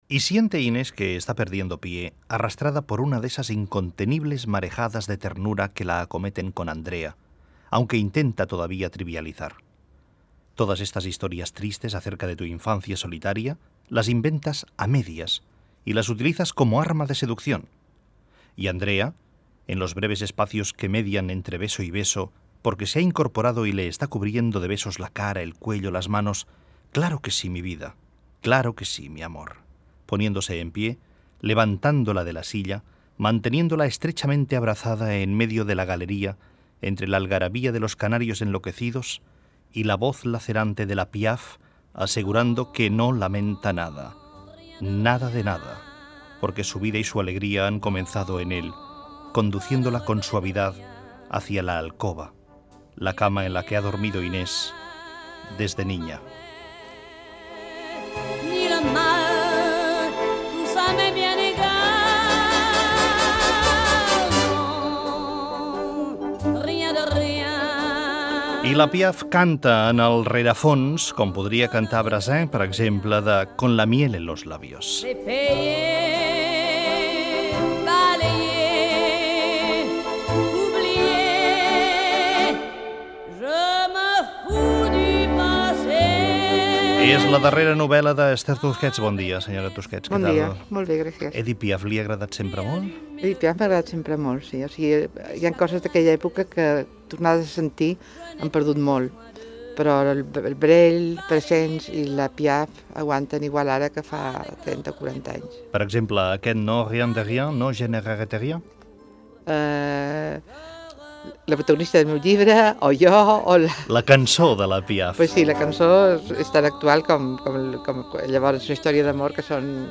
Lectura d'un passatge del llibre del qual es parlarà i fragment d'una entrevista a l'editora i escriptora Esther Tusquets amb motiu de la publicació de la seva novel·la "Con la miel en los labios".
Info-entreteniment